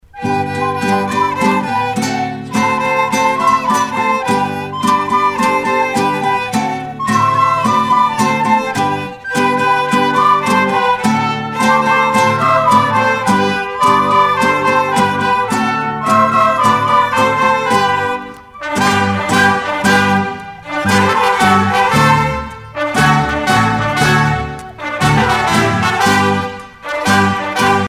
danse : raspa
Pièce musicale éditée